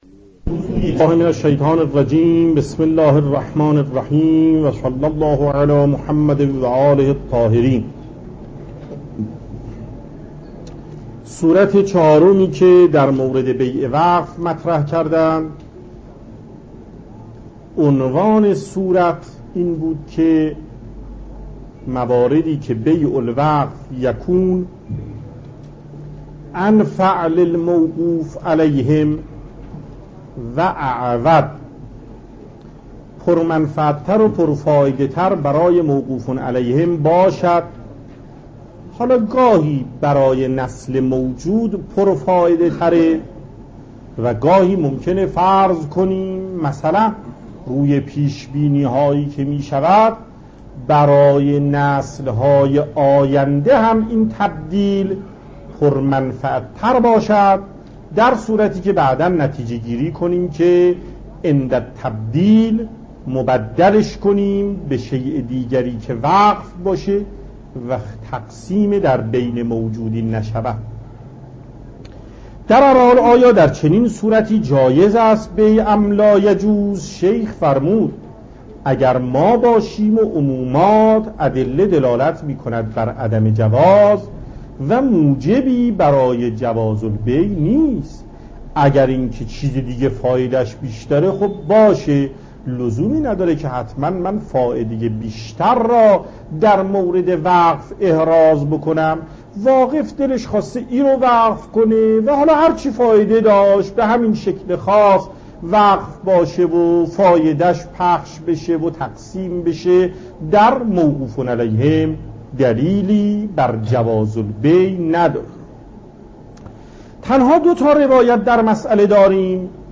درس مکاسب ایت الله محقق داماد